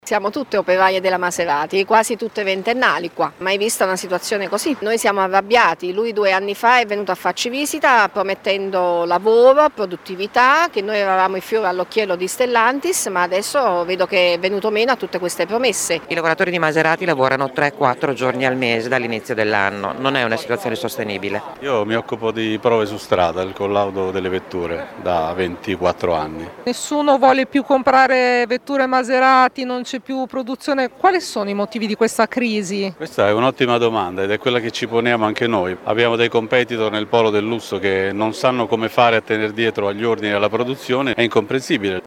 Di seguito le interviste ad alcuni lavoratori in presidio questa mattina in via Ciro Menotti: